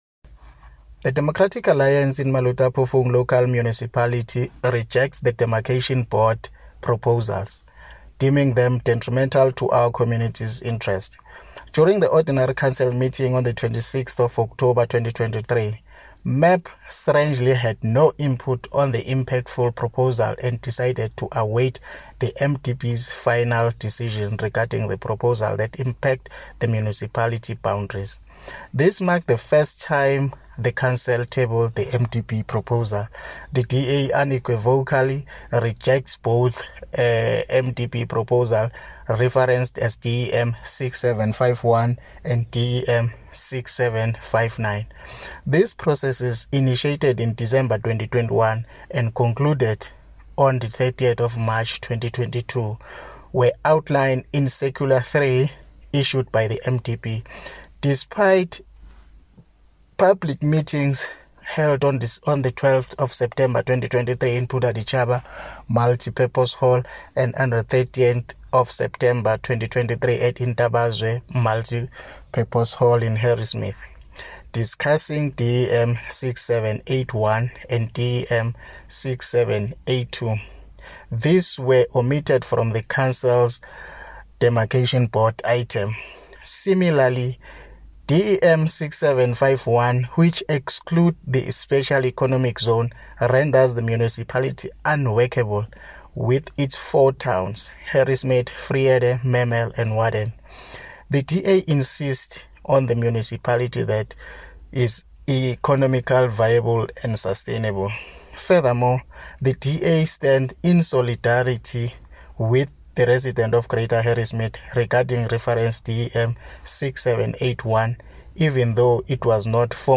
English soundbite by Cllr Richard Khumalo,